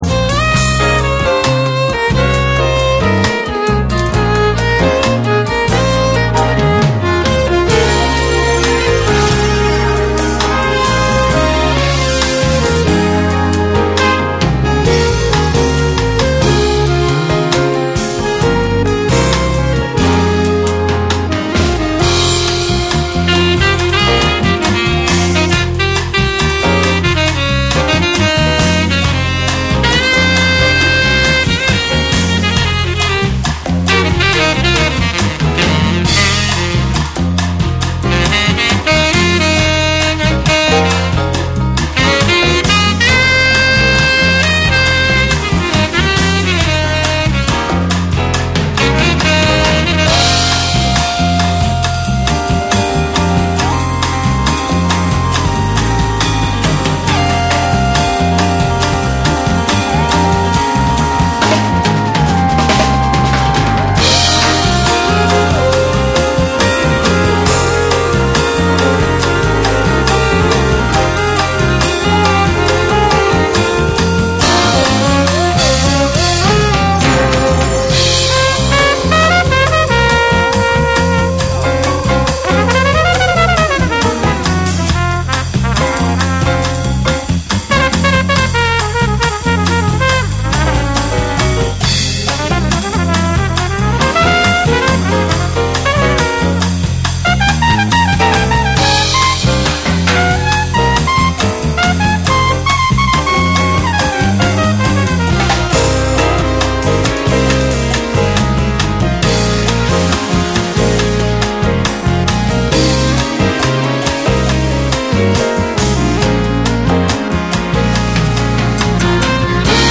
BREAKBEATS / TECHNO & HOUSE